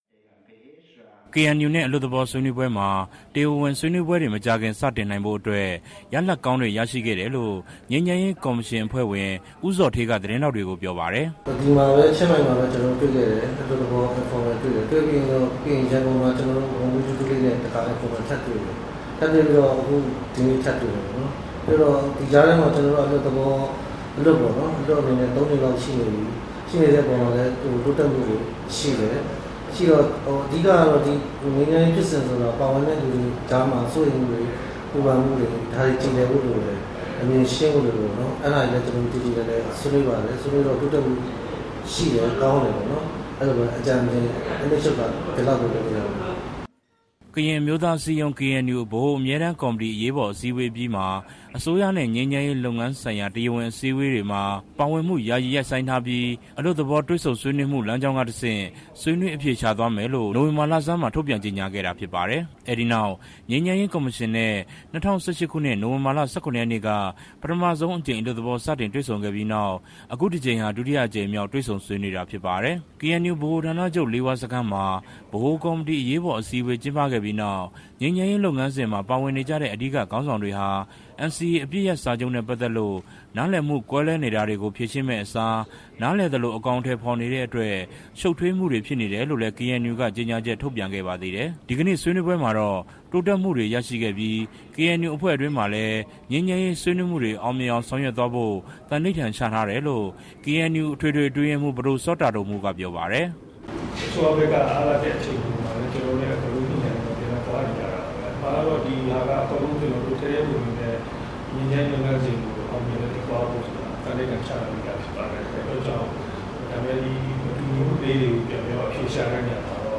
ထိုင်းနိုင်ငံ ချင်းမိုင်မြို့မှာ ဒီနေ့ ငြိမ်းချမ်းရေးကော်မရှင်နဲ့ KNU နှစ်ဖက်ခေါင်းဆောင်တွေ လေးနာရီကြာ အလွတ်သဘော တေ့ွဆုံဆွေးနွေးပွဲအပြီးမှာ သတင်းထောက်တွေကို ဦးဇော်ဌေး က ပြောခဲ့တာဖြစ်ပါတယ်။